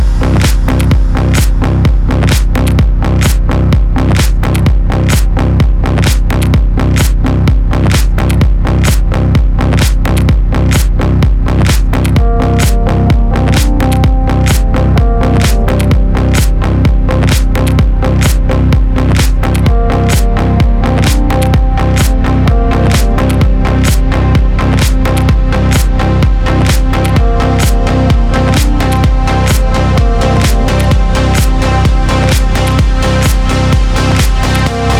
Жанр: Поп музыка / Рок / Альтернатива / Электроника